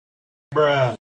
bruh-sound-effect-2_v4PECO0.mp3